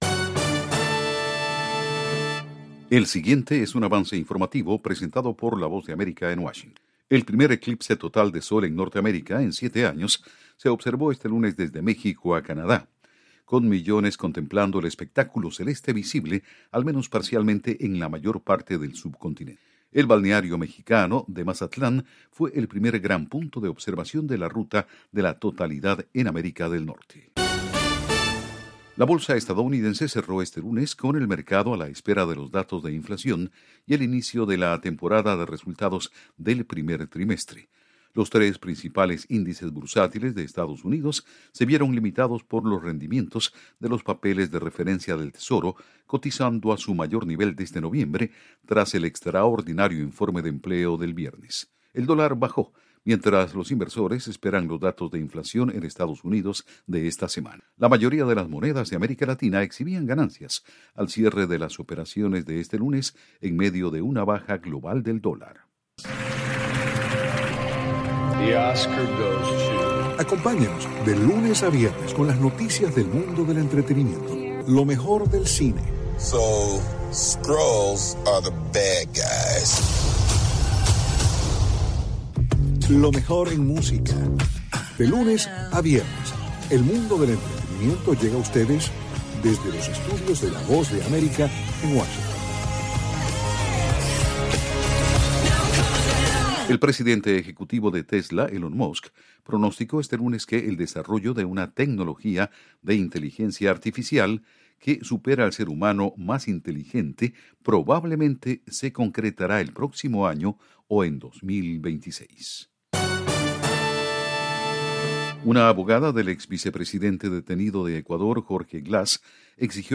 El siguiente es un avance informative presentado por la Voz de America en Washington